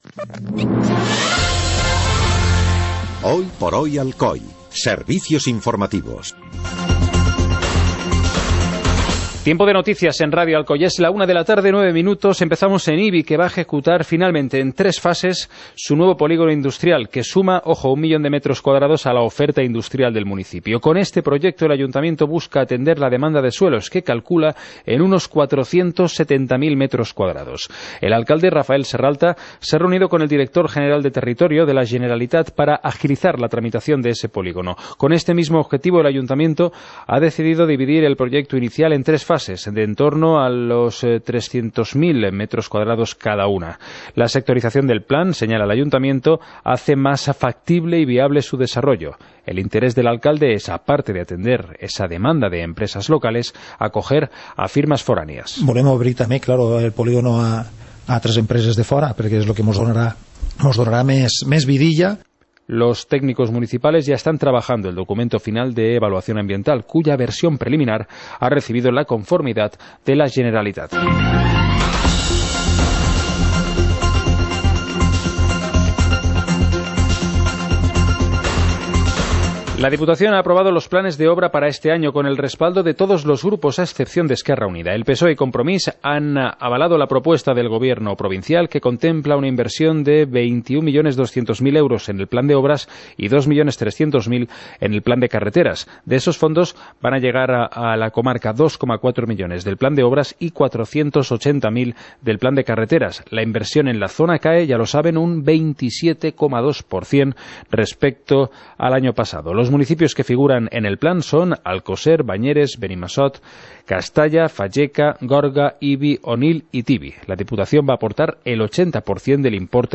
Informativo comarcal - jueves, 02 de marzo de 2017